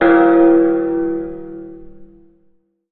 bell_large_ringing_01.wav